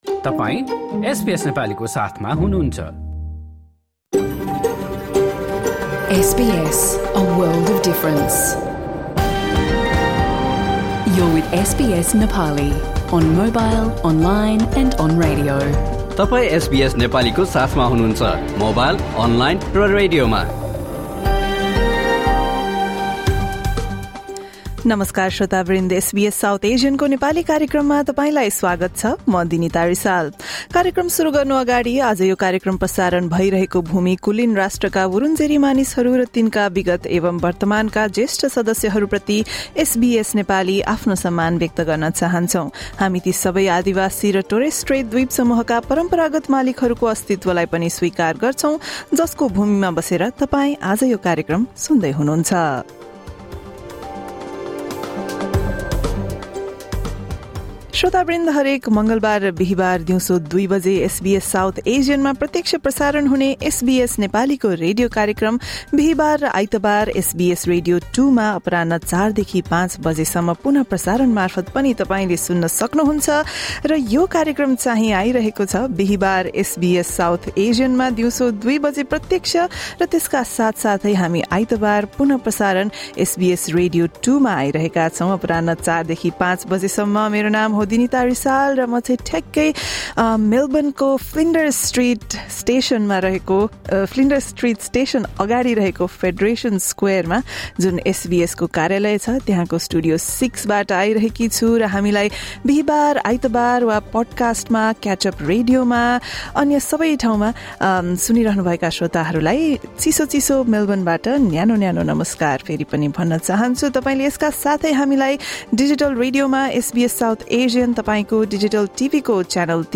हाम्रो रेडियो कार्यक्रम हरेक मङ्गलवार र बिहीवार दिउँसो दुई बजे SBS South Asian मा प्रत्यक्ष प्रसारण हुन्छ।
Listen to the SBS Nepali program broadcast on Thursday, 4 December 2025.